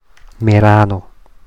Standarditalienische Form
[meˈraːno]
Merano_Standard.mp3